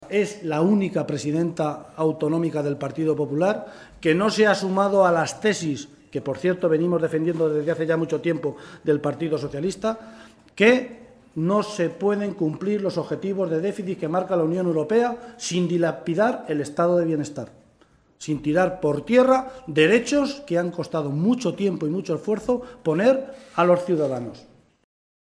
El portavoz parlamentario del PSOE en las Cortes de Castilla-La Mancha, José Luis Martínez Guijarro, ha comparecido ante los medios en la Cámara Regional para valorar la intervención de la Presidenta de Castilla-La Mancha, ante el Pleno durante el debate sobre la evolución del desempleo en nuestra región.
Cortes de audio de la rueda de prensa